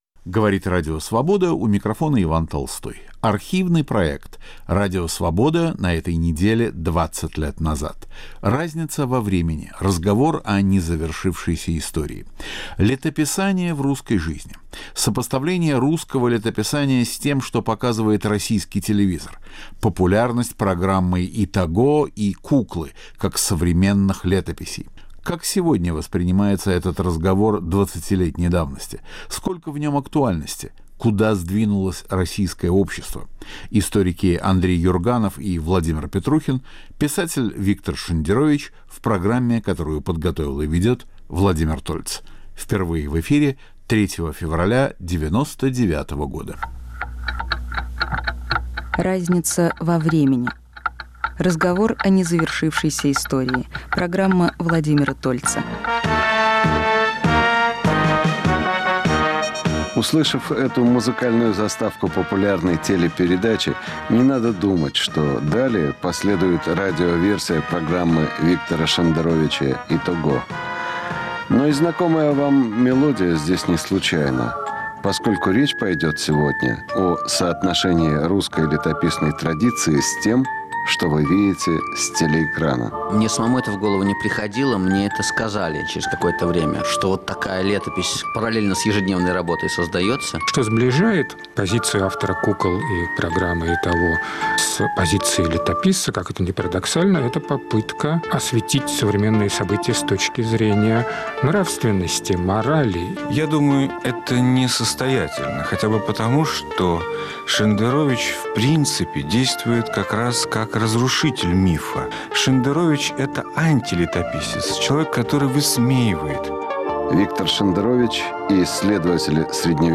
Могла ли Россия пойти другим путем? Радио Свобода на этой неделе 20 лет назад. Архивный проект.